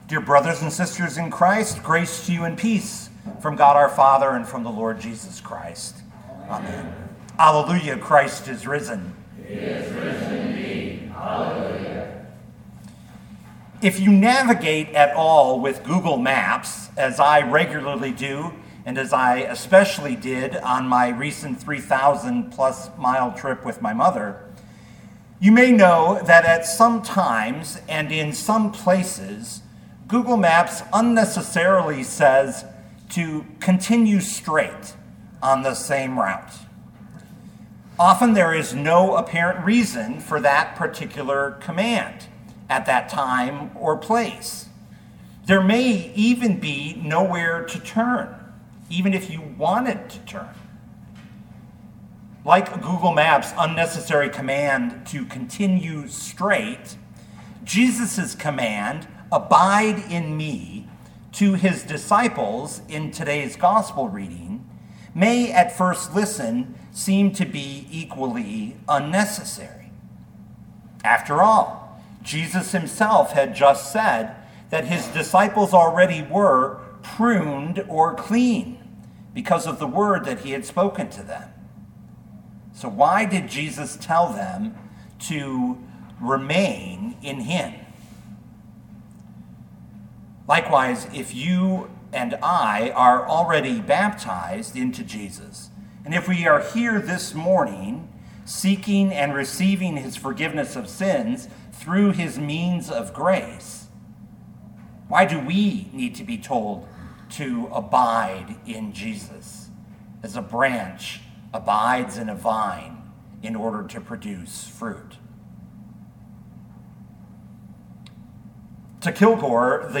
2024 John 15:1-8 Listen to the sermon with the player below, or, download the audio.